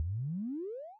action_press_time.wav